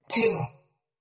descargar sonido mp3 robot mujer
contar_1.mp3